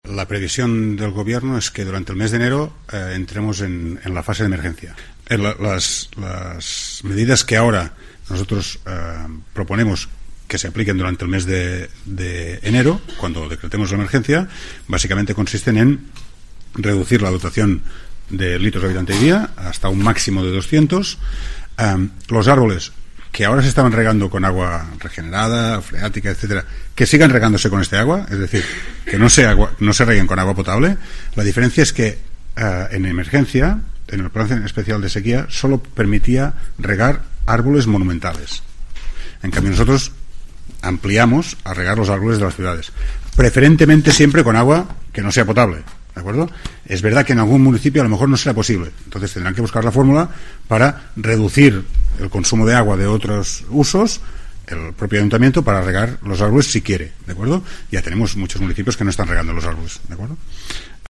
El conseller de Acción Climática David Mascort, anuncia que en enero se habrá emergencia por sequía
"Tenemos que tener todos los escenarios previstos. Si el agua vendrá de Tarragona, Palma de Mallorca o Marsella, se verá", ha insistido en rueda de prensa este martes tras la reunión semanal del govern.